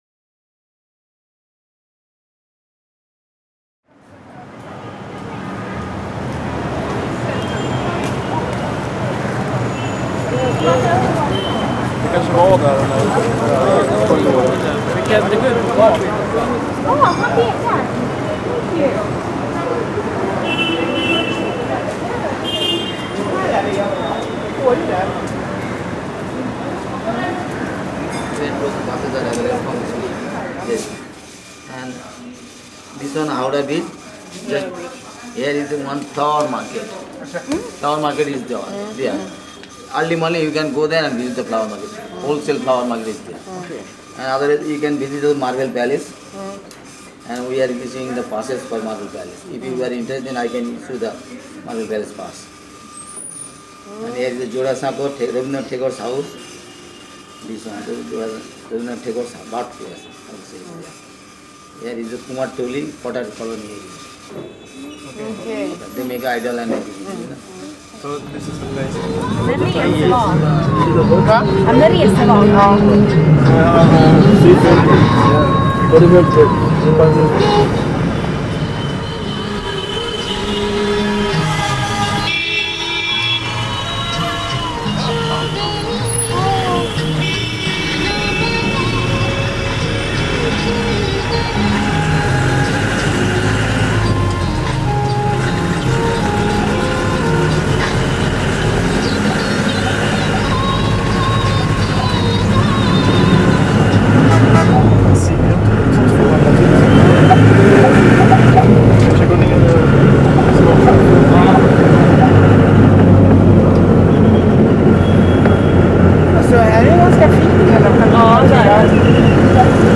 5 minutes Kolkata ( Calcutta )
5-minutes-kolkata.mp3